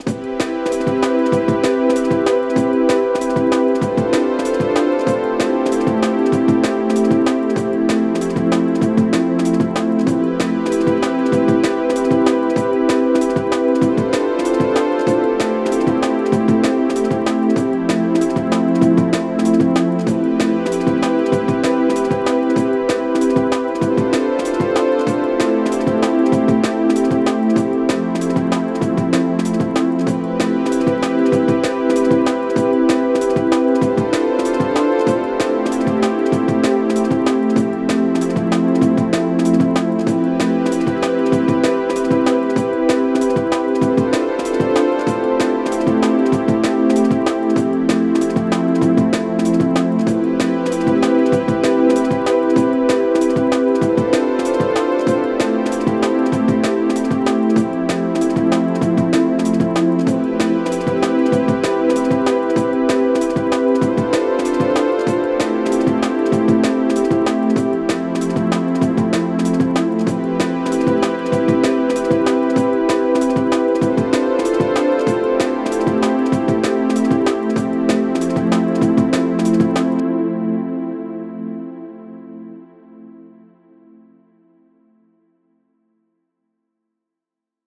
Dnb
DNB - 4:8:26, 8.27 PM.m4a 📥 (2.06 MB)